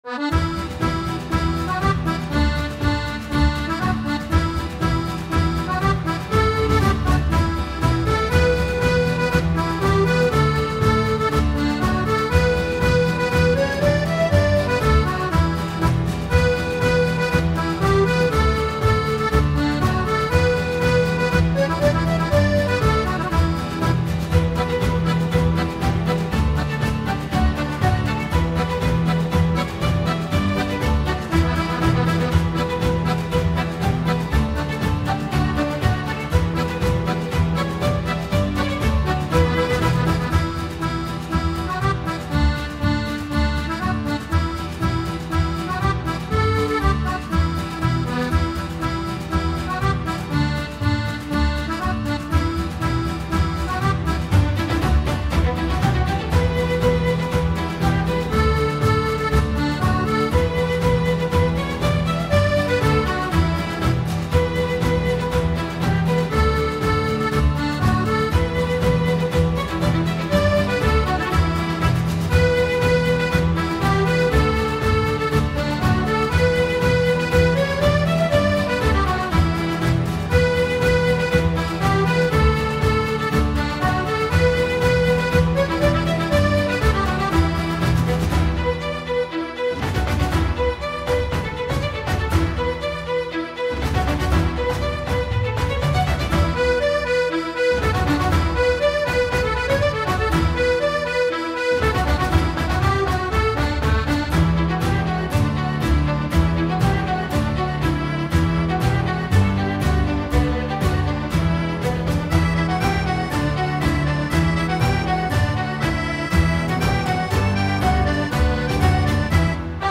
Drunken Sailor _ Sea Shanty _ Instrumental [97cYTcfhgMg].mp3